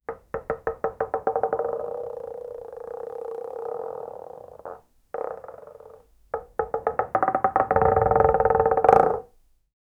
heavy marble ball sliding on wooden floor
heavy-marble-ball-sliding-7ik5h2cv.wav